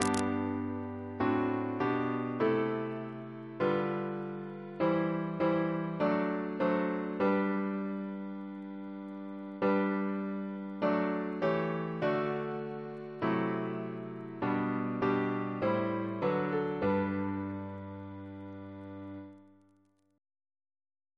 Double chant in G Composer: Thomas Attwood (1765-1838), Organist of St. Paul's Cathedral Reference psalters: ACB: 320; ACP: 264; CWP: 46; RSCM: 10